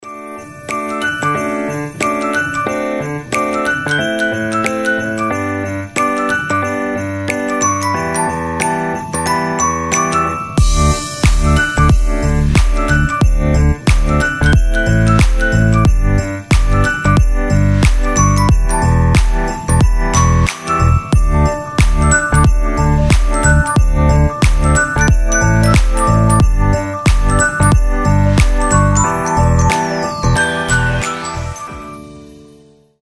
• Качество: 320, Stereo
мелодичные
без слов
пианино
колокольчики
рождественские
Светлая праздничная мелодия в духе Рождества